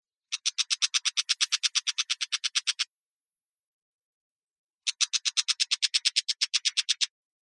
アカモズ｜日本の鳥百科｜サントリーの愛鳥活動
「日本の鳥百科」アカモズの紹介です（鳴き声あり）。